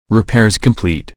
repaircomplete.ogg